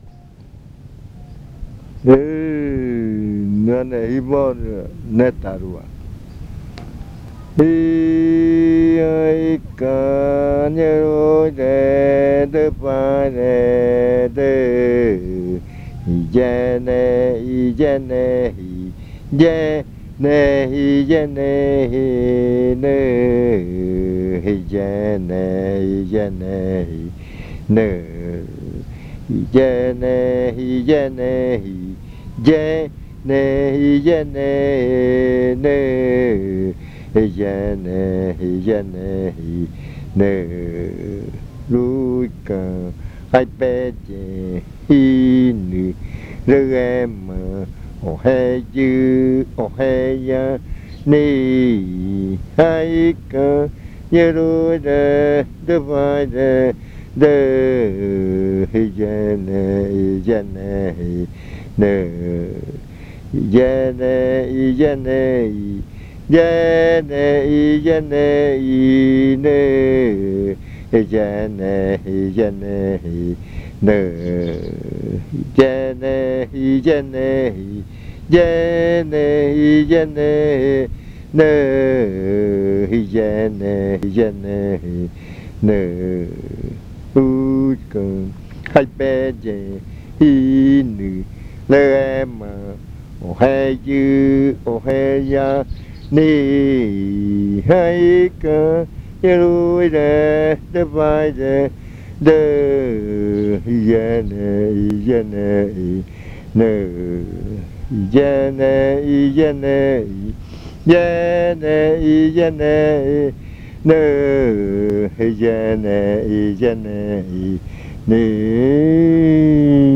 Leticia, Amazonas
A chant that says, dance on the other side of the big river.
Cantos de yuakɨ